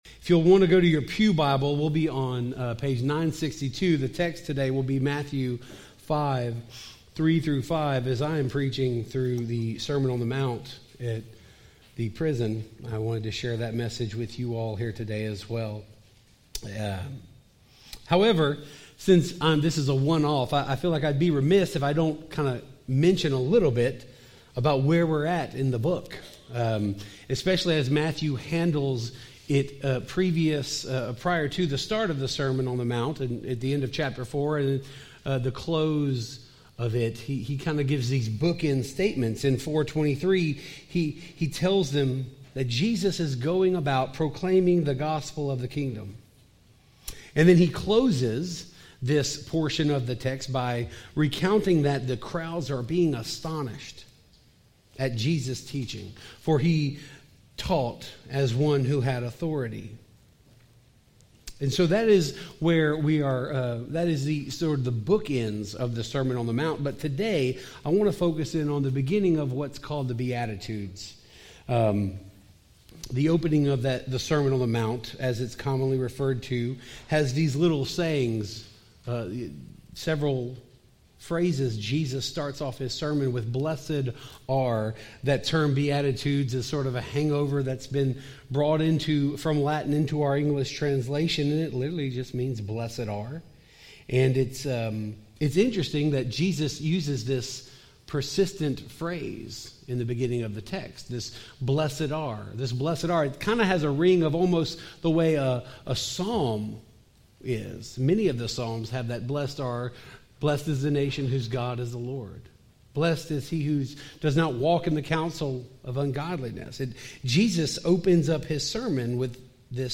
We continue our sermon series this morning on the Book of Ezra, and we arrive today at Chapter 3, which is one of the most important parts of Ezra’s narrative.